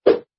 铁匠-剑类武器音效.mp3